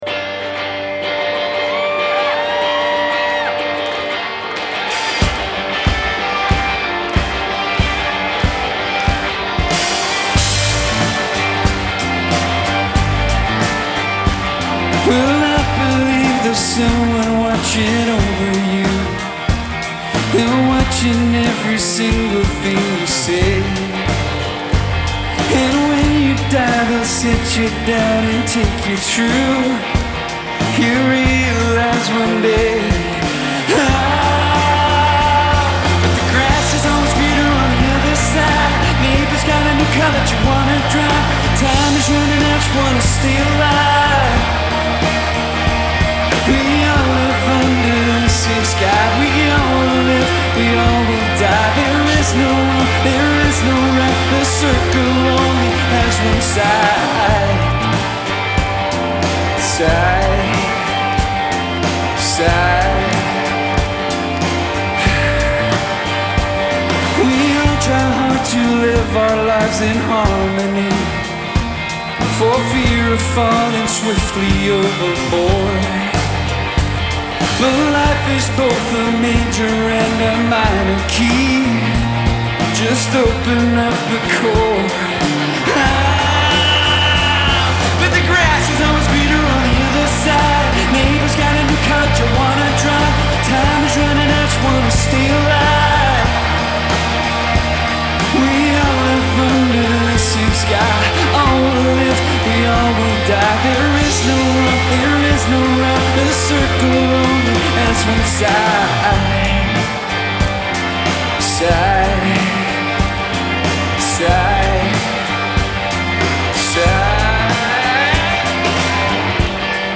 Genre : Alternatif et Indé